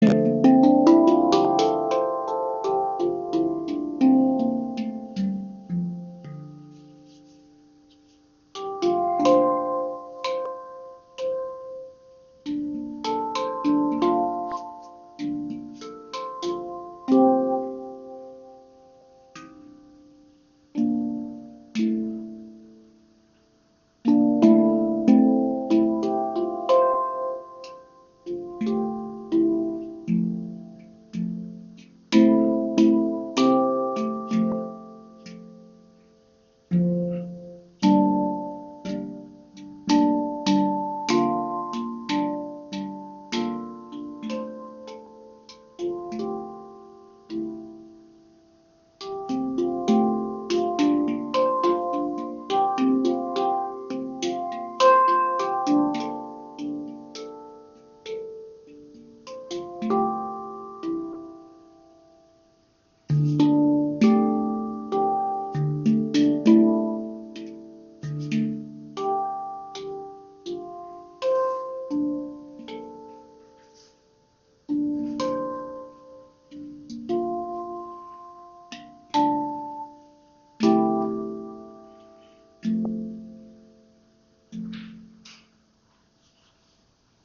Handpan D Kurd aus Edelstahl mit 12 Tonfeldern – warm, offen, mystisch • Raven Spirit
Entdecke die D Kurd Handpan aus Edelstahl mit 12 Tonfeldern inklusive Bodennoten. Warm klingend, mit orientalischem Flair, perfekt für Meditation, kreative Improvisationen und entspannte Sessions.
Klangbeispiel
D Kurd – Warm, offen und tiefgründig Die D-Kurd-Stimmung verzaubert mit erdiger Wärme und geheimnisvoller Tiefe.